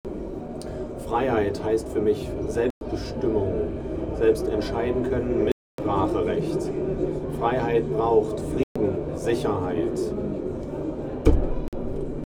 Standort der Erzählbox: